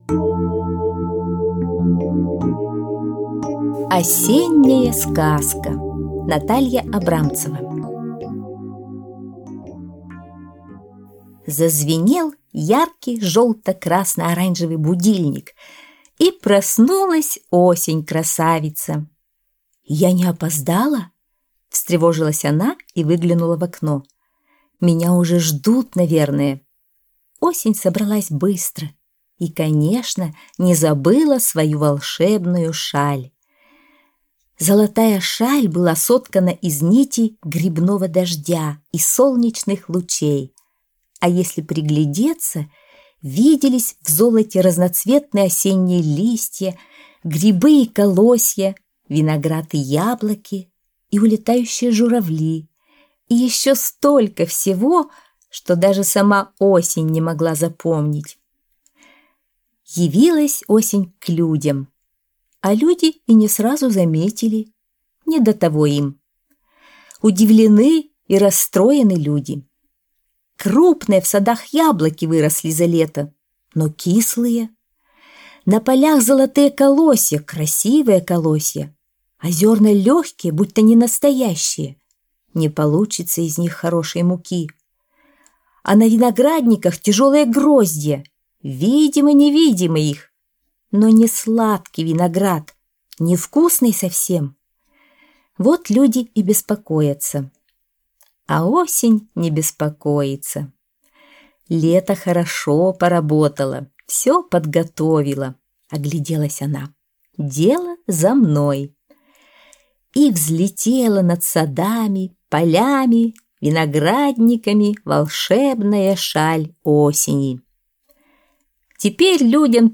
Осенняя сказка (аудиоверсия)